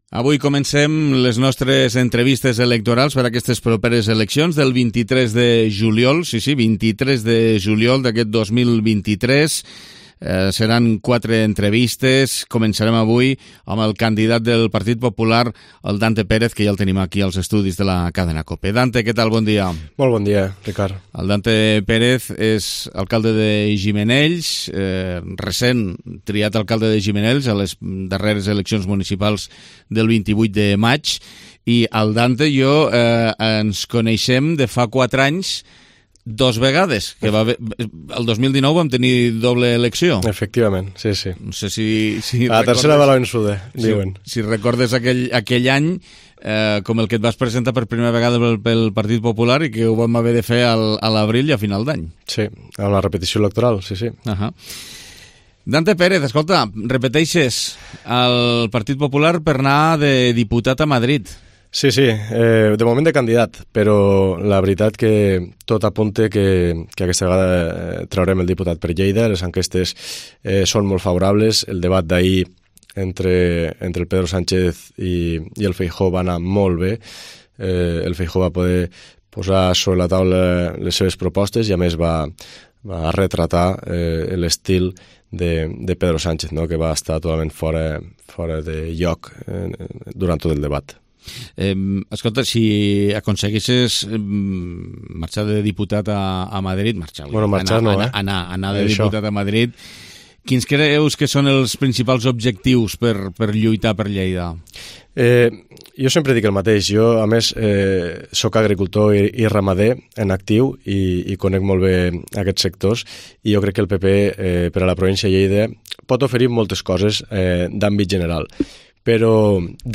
Entrevista Campanya Electoral 23J2023